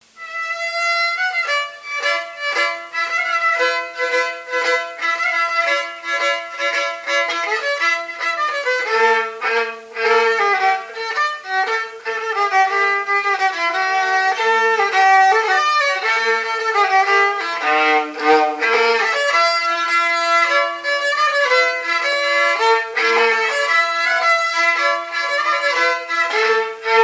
Localisation Montreuil (Plus d'informations sur Wikipedia)
Fonction d'après l'analyste danse : quadrille : poule ;
Catégorie Pièce musicale inédite